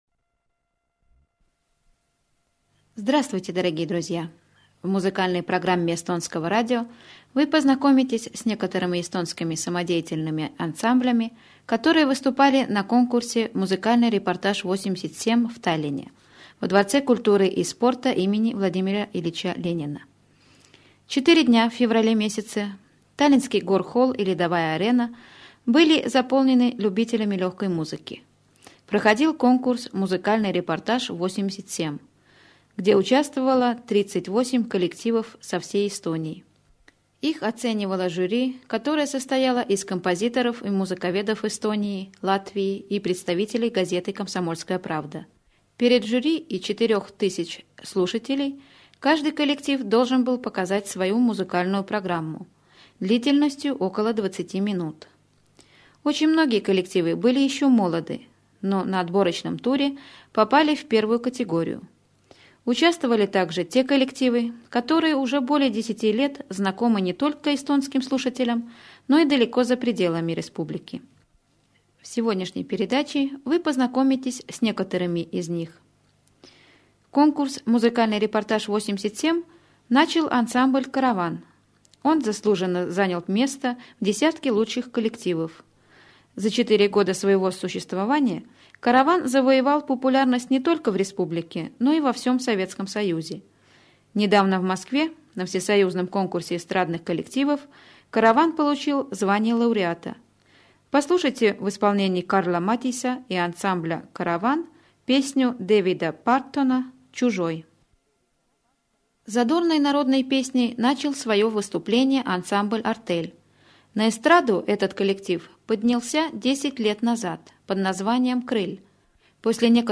Ув.Завалинцы,и я прошу Вашей помощи!Имеется сырой выпуск муз.передачи эстонского радио 87г. -только объявления ведущей-нет самих муз.номеров,которые я и надеюсь разыскать!
муз репортаж 87 таллин.mp3